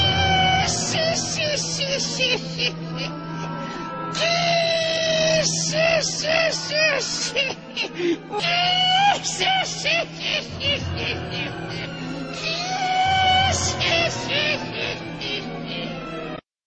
Gekko Moriah Laugh